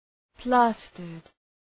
Προφορά
{‘plæstərd}